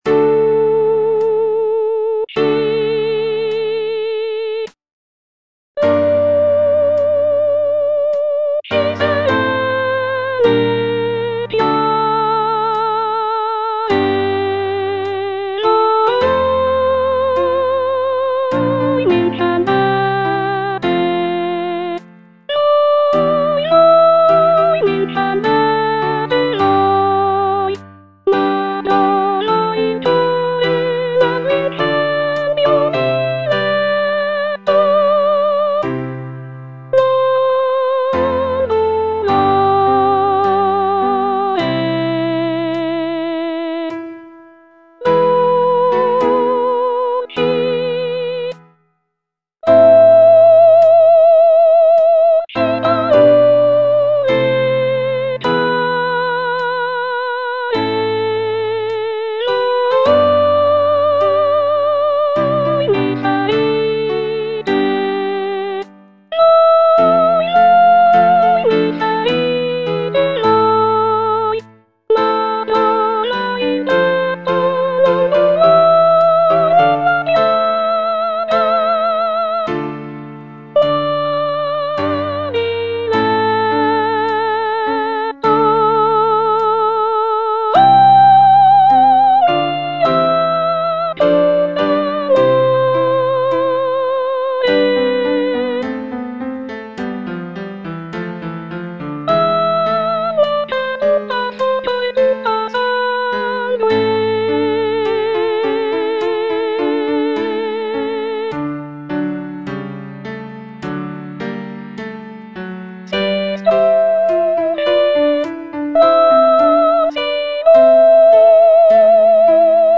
C. MONTEVERDI - LUCI SERENE E CHIARE Soprano I (Voice with metronome) Ads stop: auto-stop
"Luci serene e chiare" is a madrigal composed by Claudio Monteverdi, one of the most important figures in the development of Baroque music.
The madrigal is known for its intricate vocal lines and rich textures, creating a sense of serenity and clarity in the music.